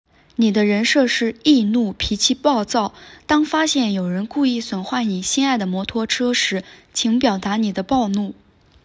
speech generation